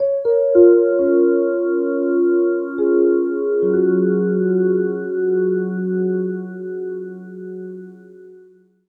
Piano Panner.wav